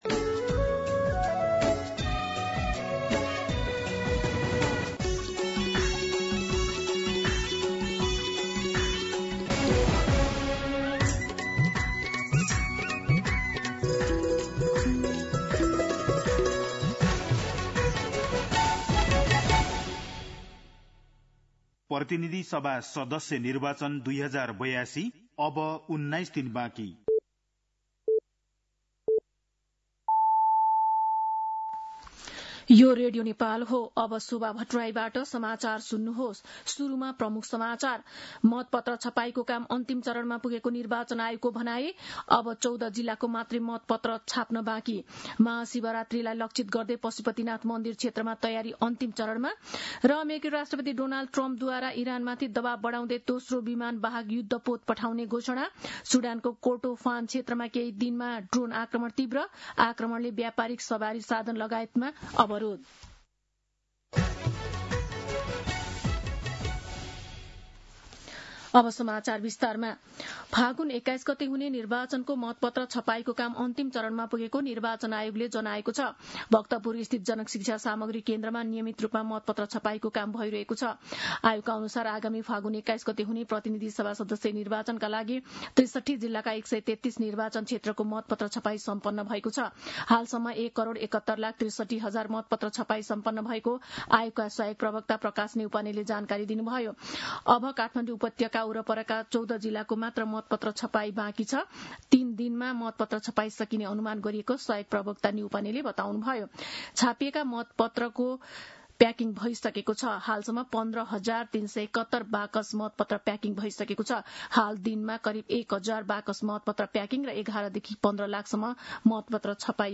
दिउँसो ३ बजेको नेपाली समाचार : २ फागुन , २०८२
3pm-Nepali-News.mp3